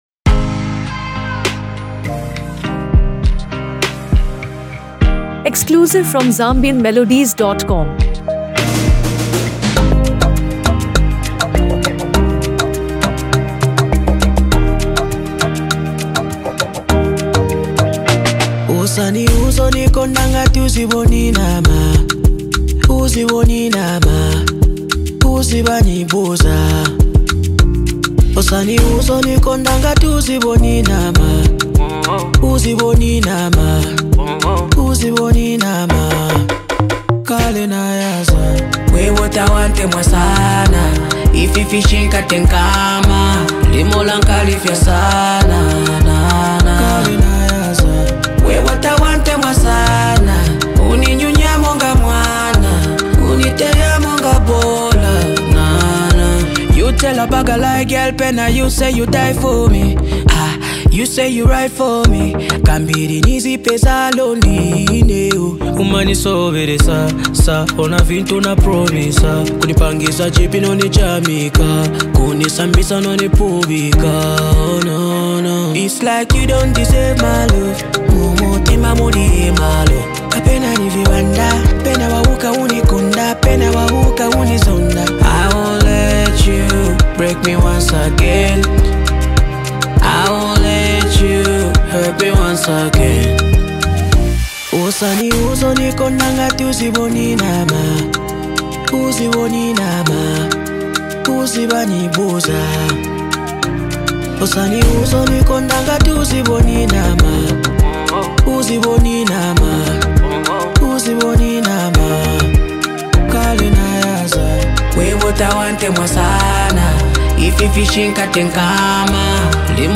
a seamless blend of rap and melody